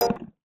UIClick_Smooth Tone Metallic 02.wav